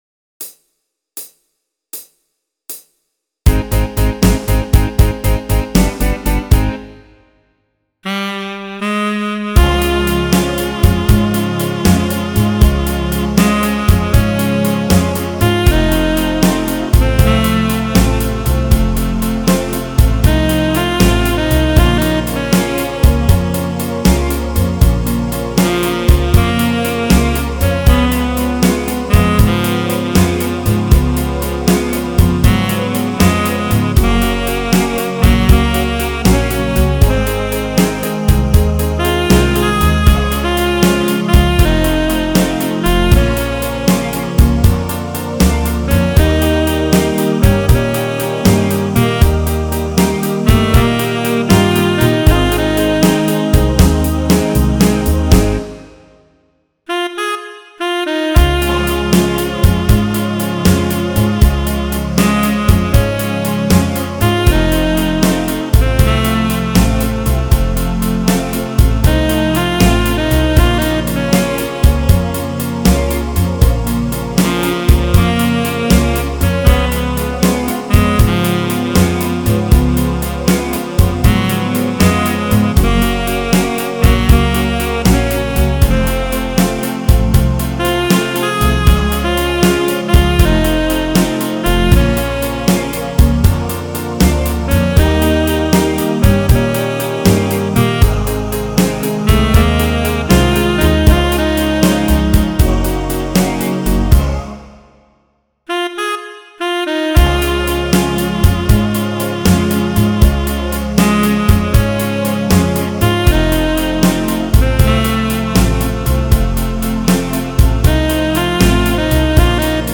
[Pop/Rock List]